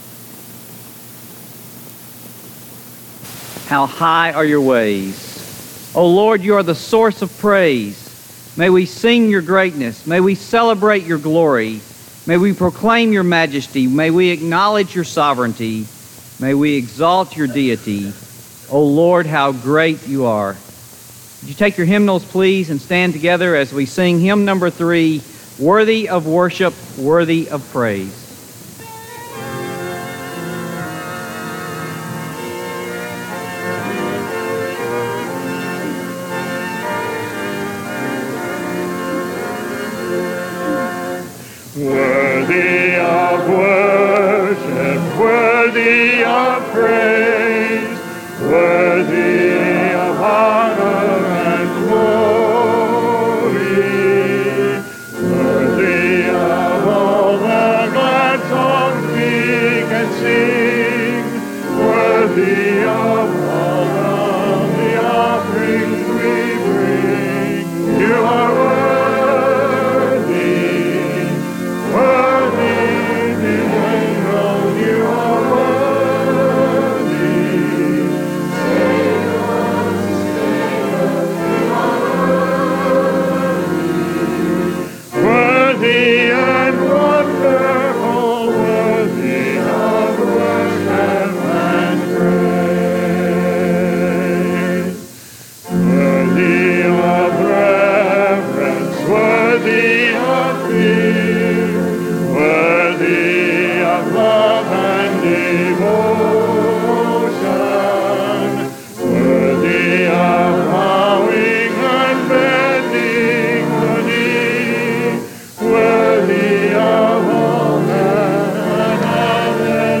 Chapel: Os Guinness